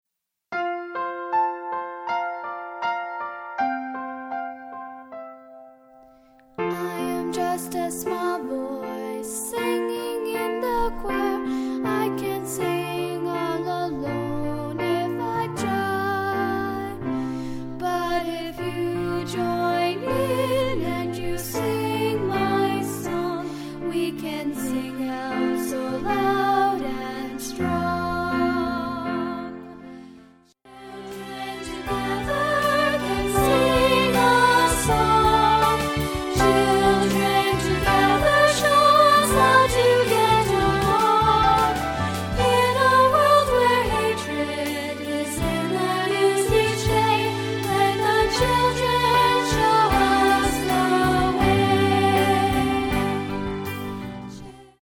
piano/vocal arrangement